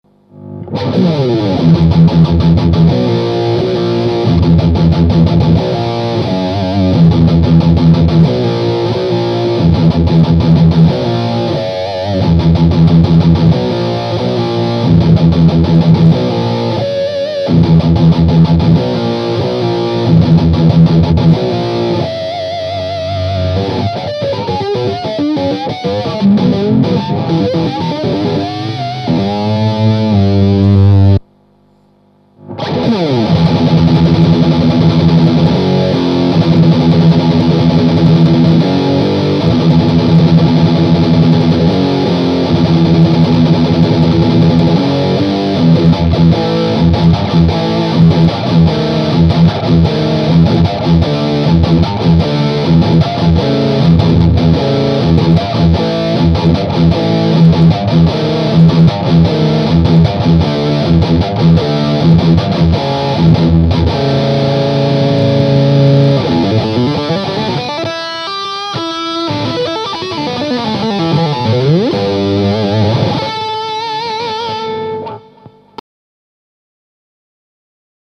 Voici une serie d'enregistrements qui ont été fait sur le Ten, l'ampli 10 watt de Pasqualiamps.
Le baffle utilisé est un "closed back" ave 2 HP Celestion G12-H. Les 3 premières plages ne contiennent aucun effet.
Plage 6 "Metal": Rythmique/Rythmique speed/Solo "
Réglages Tactile Custom: Micro manche simple (splité) Tone 100 % - Volume 100 %
Réglage Pasqualiamps Ten: Tone 5 - Volume 1.5
Réglages des effets: Geoges Dennis (Gain: 10 Tone 2 Level 5)